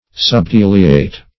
Search Result for " subtiliate" : The Collaborative International Dictionary of English v.0.48: Subtiliate \Sub*til"i*ate\, v. t. [LL. subtiliare.] To make thin or rare.